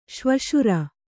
♪ śvaśura